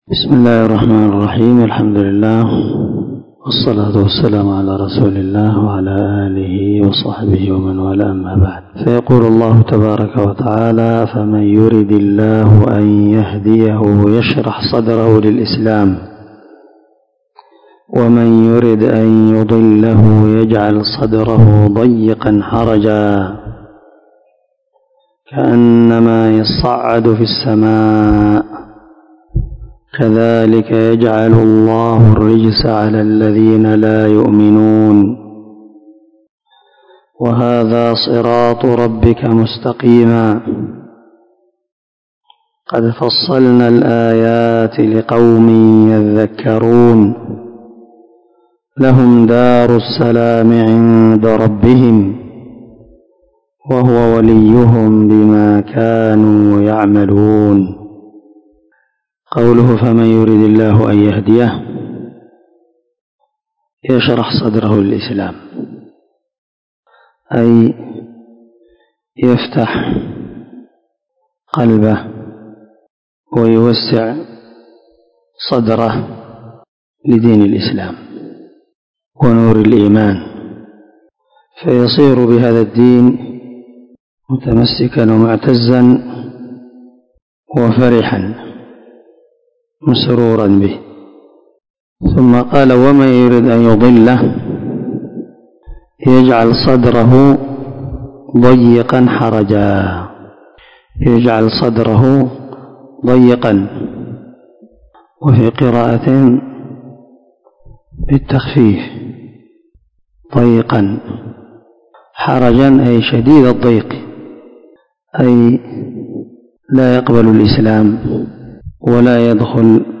434الدرس 42 تفسير آية ( 125 – 127 ) من سورة الأنعام من تفسير القران الكريم مع قراءة لتفسير السعدي
دار الحديث- المَحاوِلة- الصبيحة.